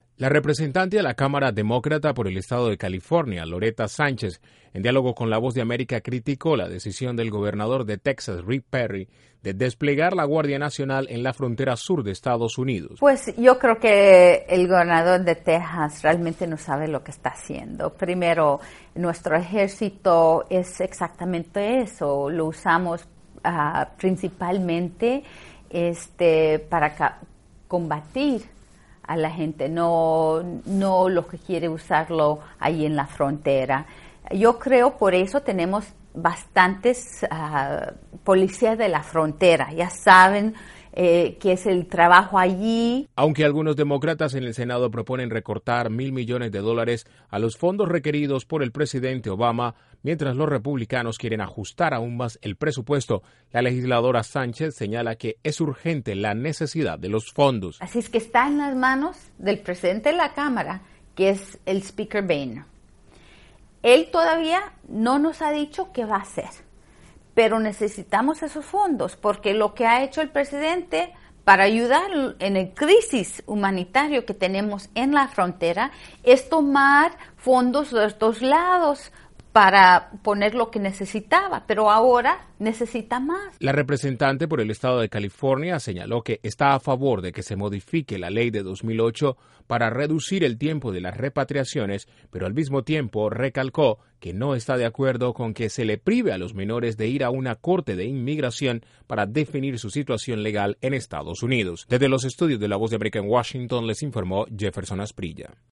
INTRO: Congresista demócrata critica el despliegue de la Guardia Nacional en Texas y urge a la aprobación de los fondos para la crisis fronteriza. Desde la Voz de América en Washington informa